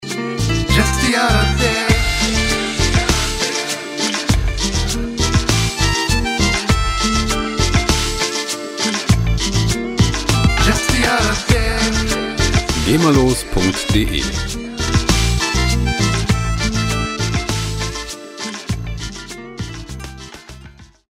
lizenzfreie Latin Musik
Musikstil: Electro Cumbia
Tempo: 100 bpm